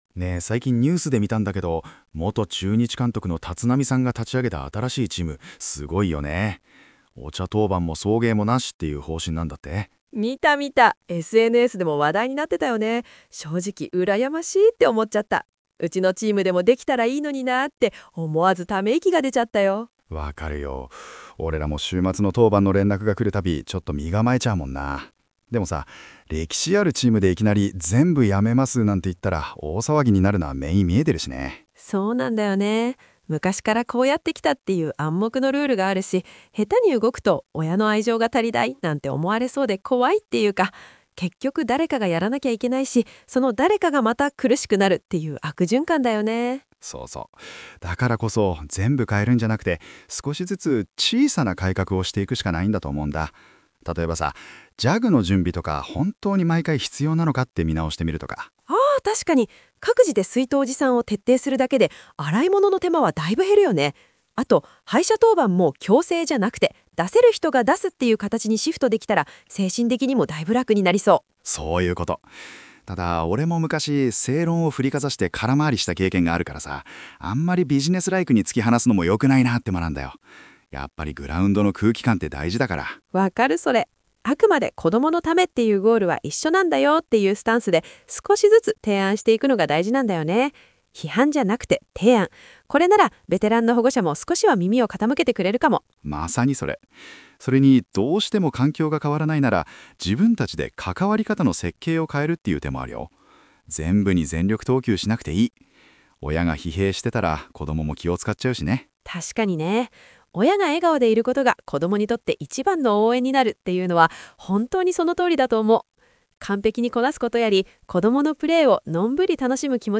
※AI生成による音声コンテンツにて、発音や読み方に違和感ございますが、ご了承ねがいます。